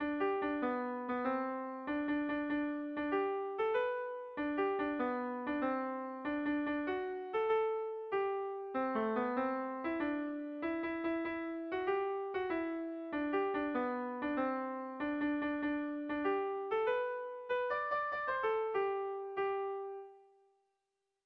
Kontakizunezkoa
Bertsolaria
Zortzikoa, txikiaren moldekoa, 4 puntuz (hg) / Lau puntukoa, txikiaren modekoa (ip)
A1A2BA1